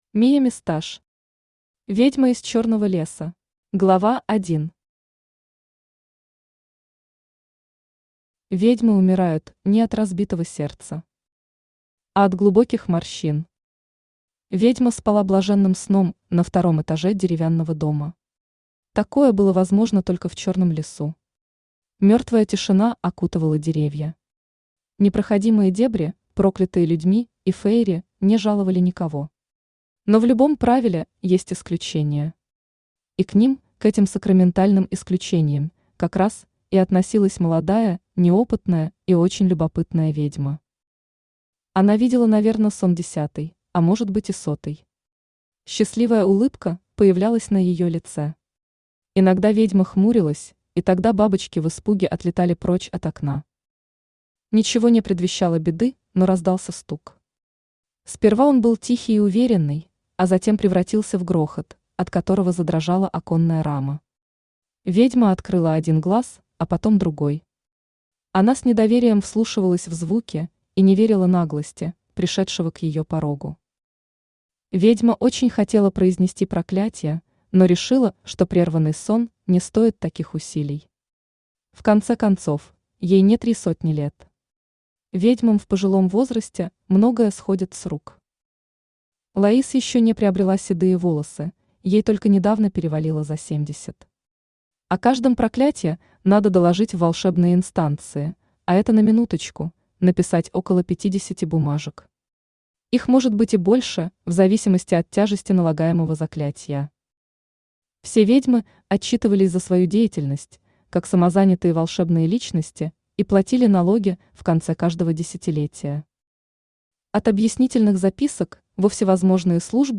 Aудиокнига Ведьма из Чёрного леса Автор Мия Мисташ Читает аудиокнигу Авточтец ЛитРес.